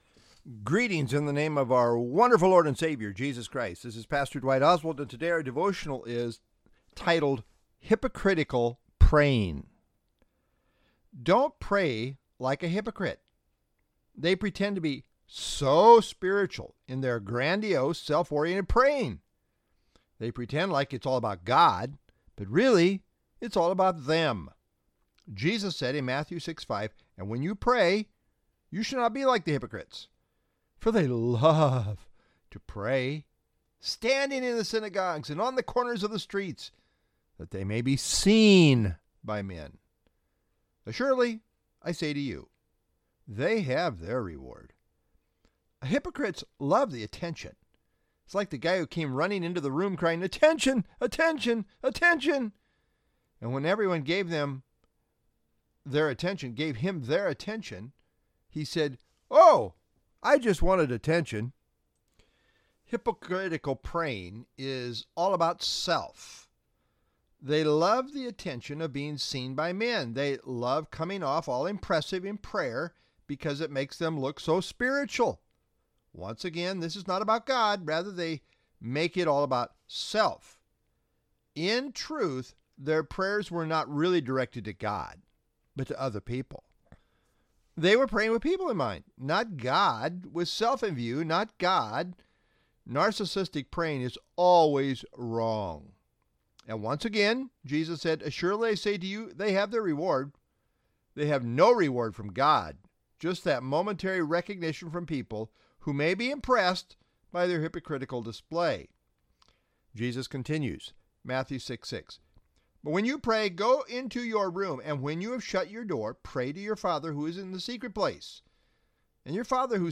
Sermons | Southview Bible Church
March 18, 2026 (Wednesday Evening)